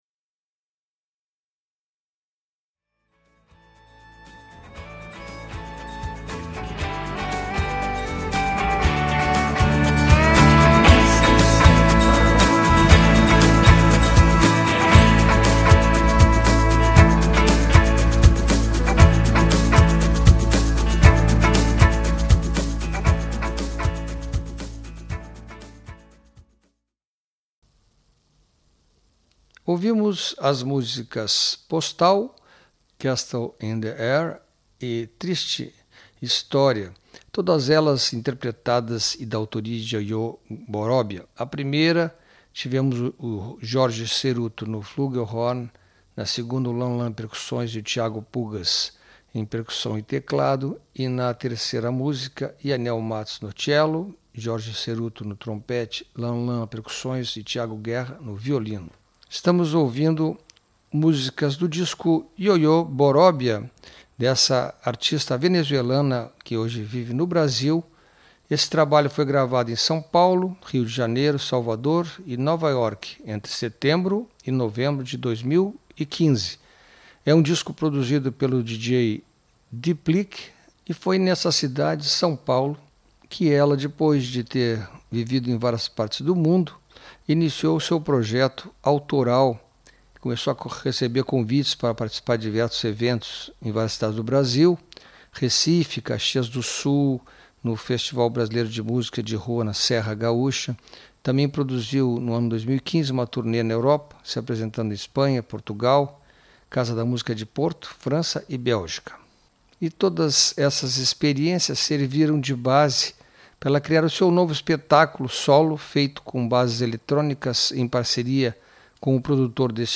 A cantora e compositora
feito com bases eletrônicas